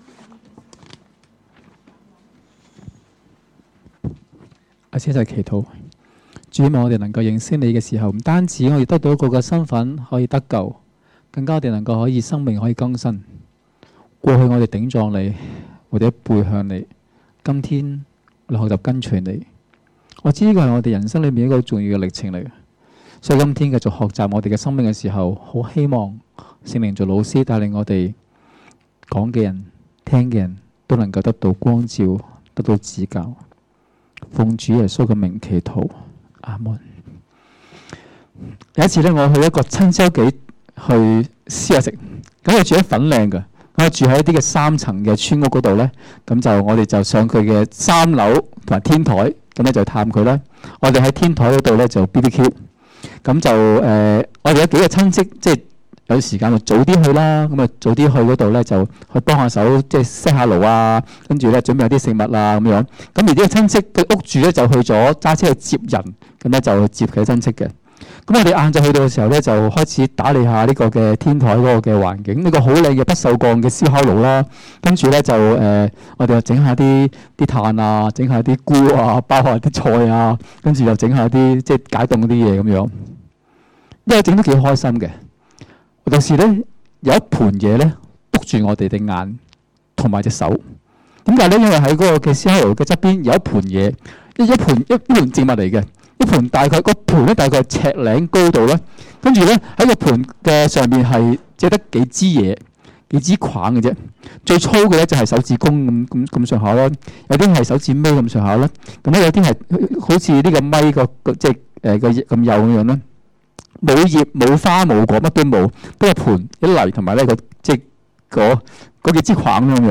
2016年7月30日及31日崇拜
2016年7月30日及31日講道